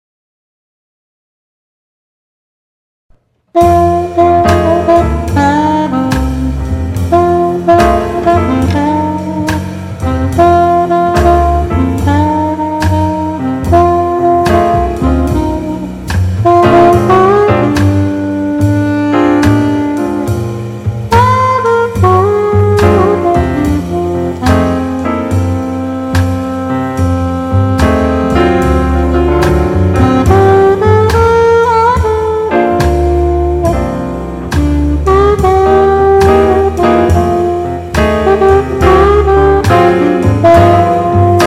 My only recording input is a dynamic mic to record Real Book tunes with my sax to imported backing tracks.
I did add minimal reverb to both tracks.
Please feel free to comment on this blues sax recording I made in Cubasis. Simply an imported audio track with a little reverb and a 2nd track recording the alto sax blues solo.